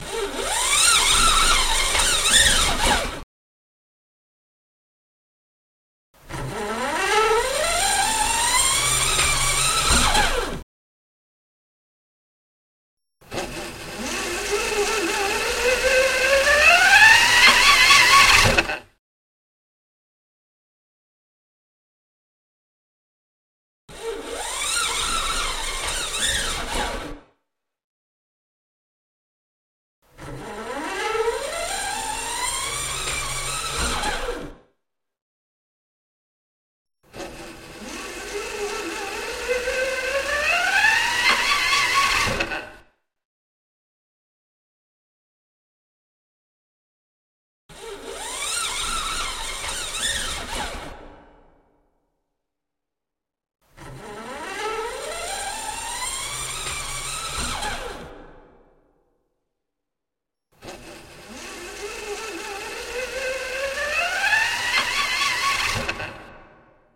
Звуки каната, троса
Звук опускания канатного троса с использованием лебедки